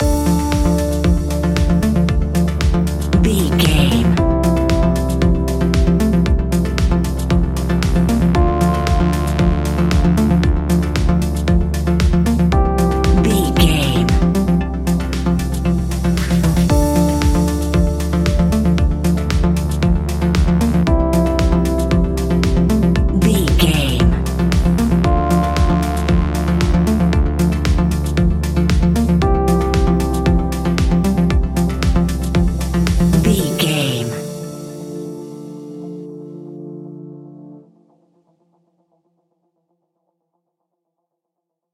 Aeolian/Minor
groovy
uplifting
futuristic
driving
energetic
synthesiser
drum machine
electric piano
dance
synthwave
synth leads
synth bass